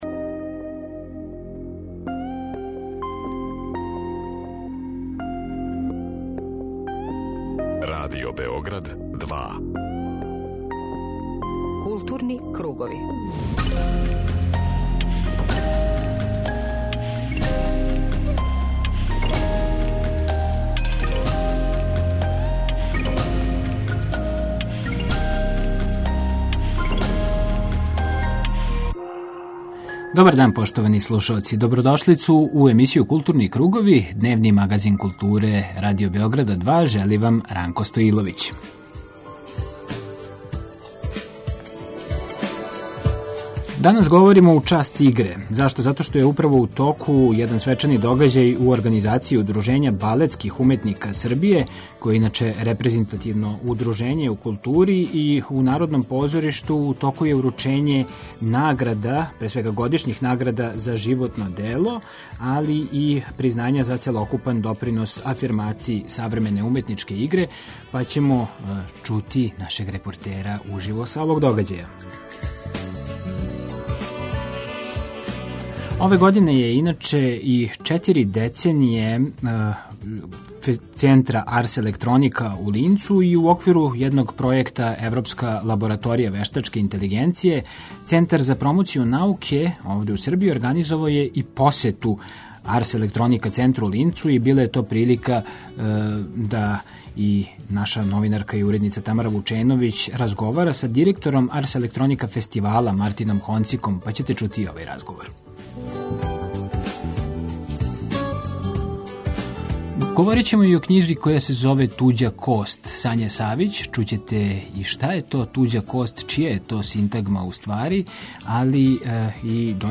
Дневни магазин културе